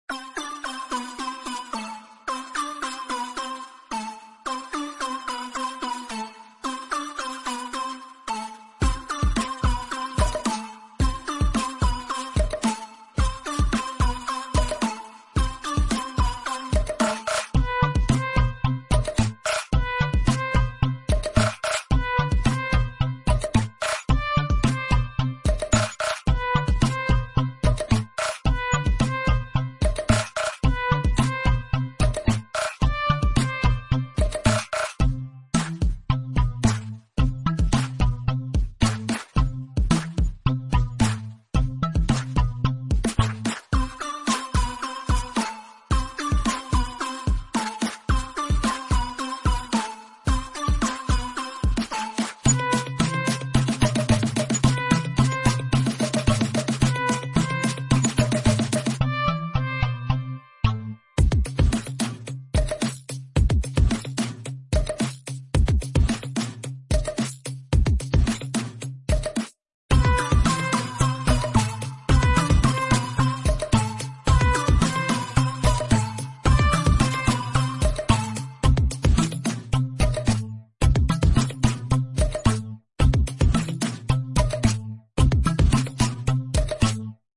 Bouncy Ball